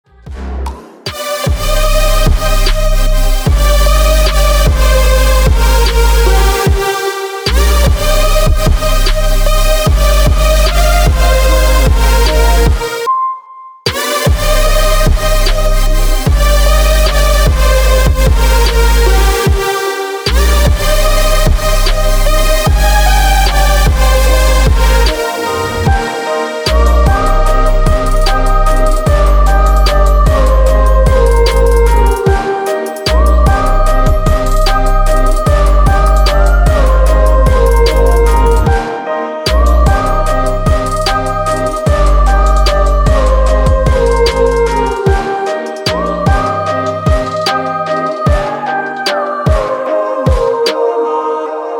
красивые
без слов
Electronica
Trap
Dubstep